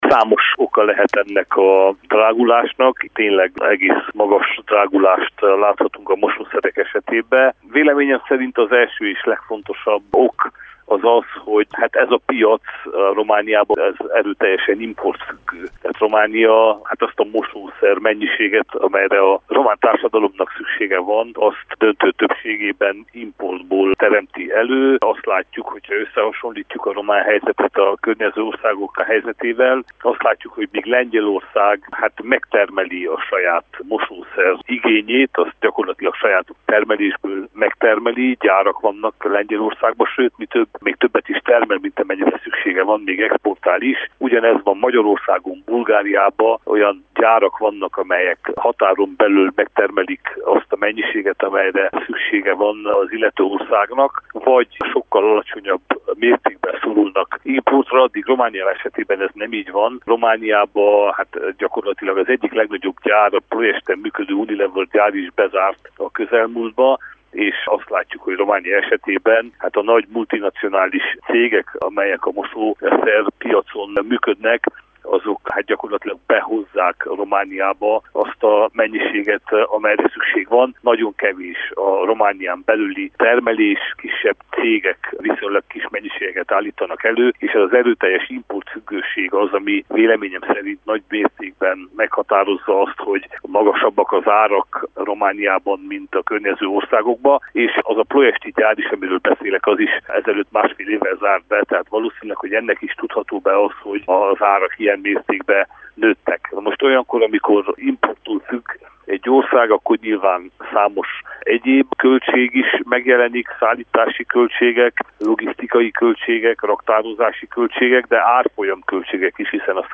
A lehetséges okokról Miklós Zoltán parlamenti képviselőt, közgazdászt kérdeztük.